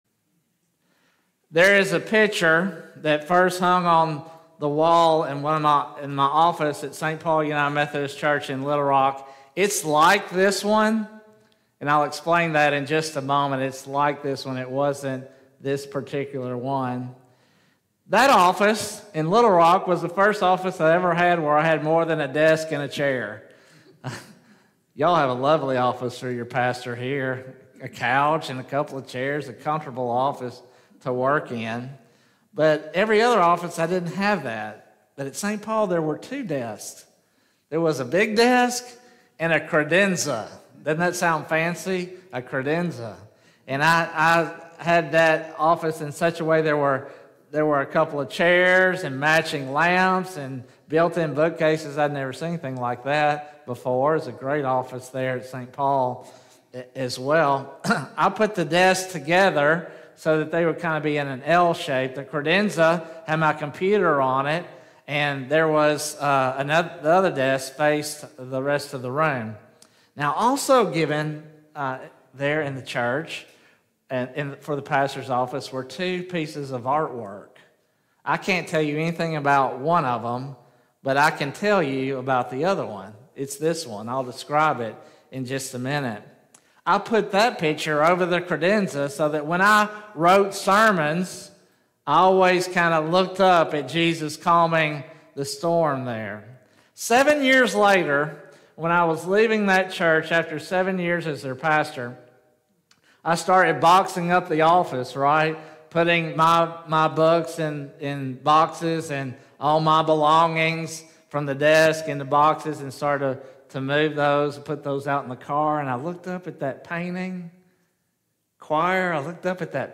Sermons | First United Methodist Church